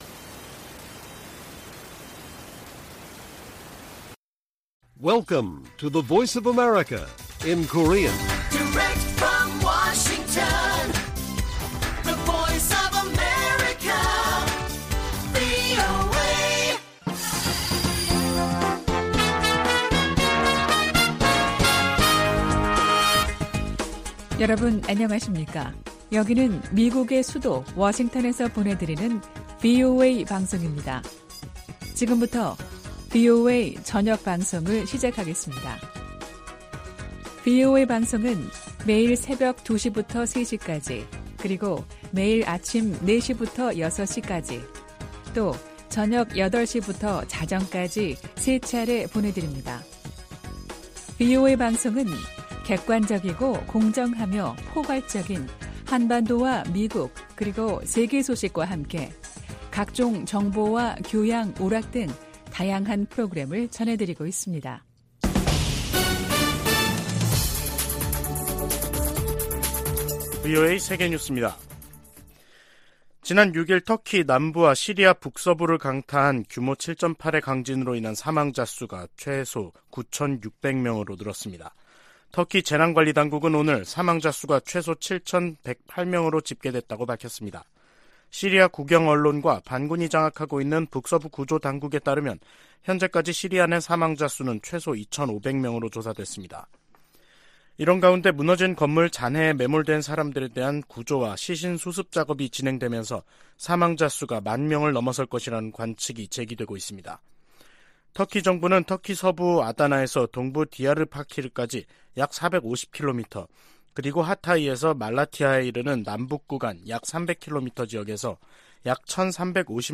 VOA 한국어 간판 뉴스 프로그램 '뉴스 투데이', 2023년 2월 8일 1부 방송입니다. 조 바이든 미국 대통령은 2일 국정연설을 통해 중국이 미국의 주권을 위협한다면 ‘우리는 나라를 보호하기 위해 행동할 것’이라고 말했습니다. 유엔이 국제적 긴장을 고조시키는 북한의 핵 개발과 미사일 발사에 우려한다는 입장을 거듭 확인했습니다.